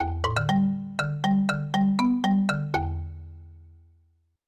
Jingle_2
cherry chimes ding jingle xylophone sound effect free sound royalty free Sound Effects